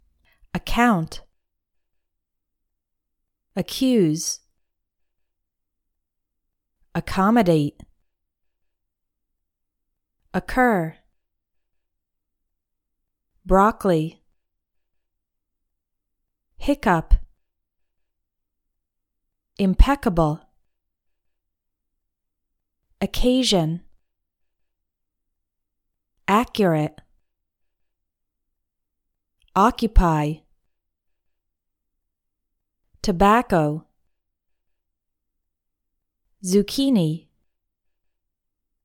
Pronounce CC like a hard K sound
Another way to pronounce CC is with a hard “K” sound.